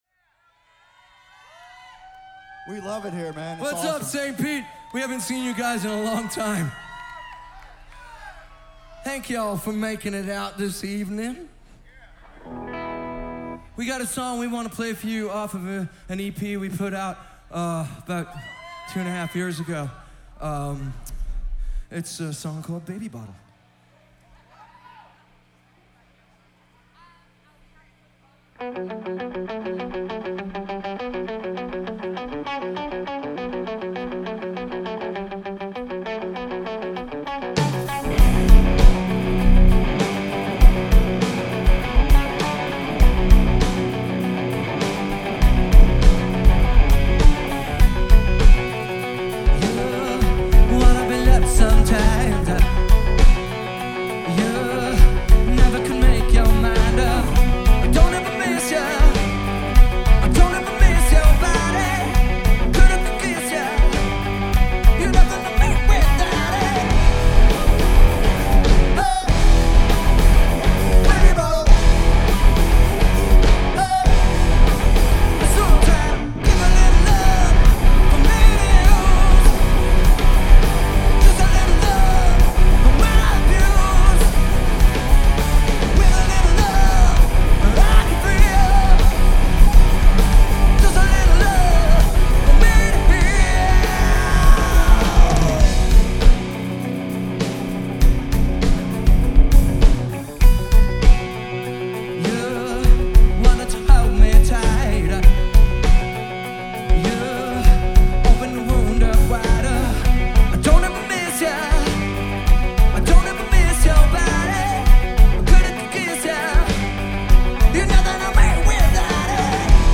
Genre: Alt.Rock.